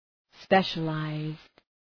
Προφορά
{‘speʃə,laızd}